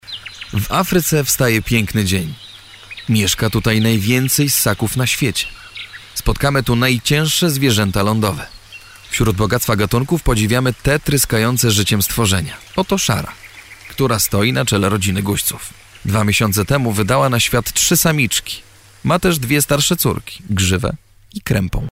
Male 20-30 lat
Mature, deep and dynamic voice.
Nagranie lektorskie